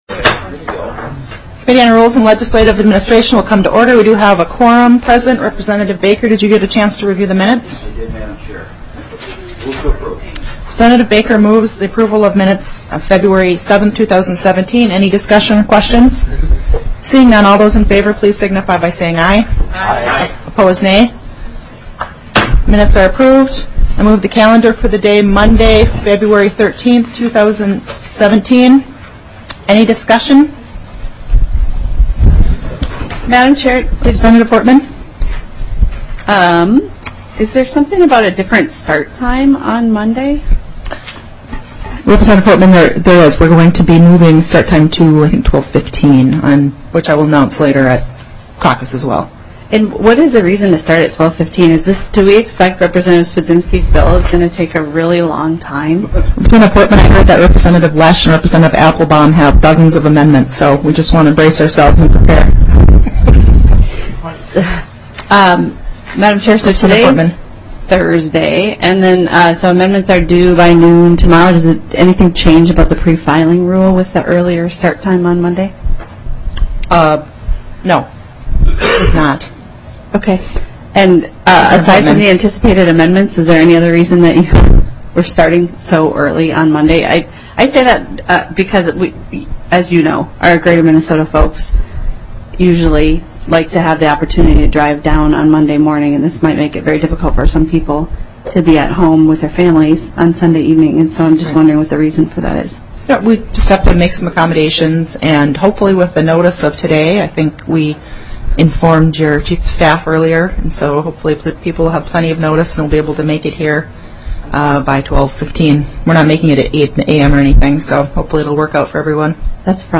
Representative Peppin, Chair of the Rules and Legislative Administration Committee, called the meeting to order at 10:08 A.M. on February 9, 2017, in the Basement Hearing Room of the State Office Building.